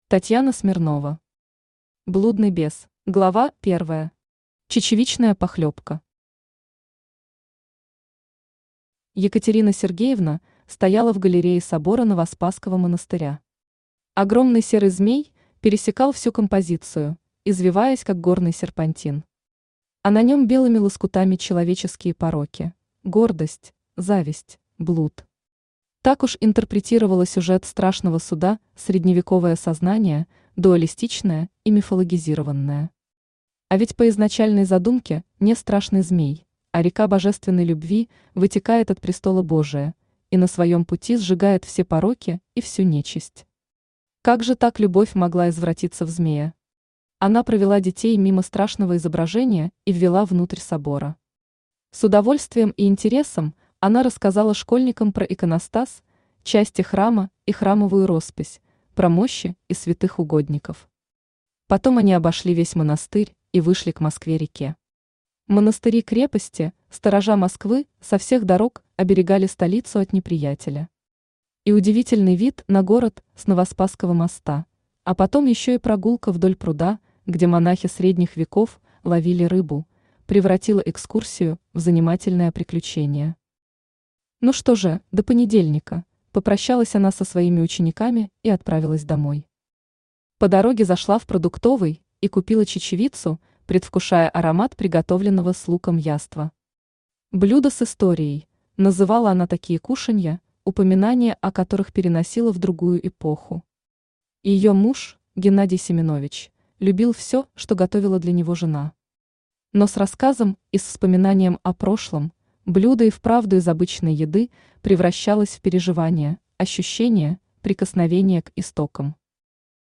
Аудиокнига Блудный бес | Библиотека аудиокниг
Aудиокнига Блудный бес Автор Татьяна Андреевна Смирнова Читает аудиокнигу Авточтец ЛитРес.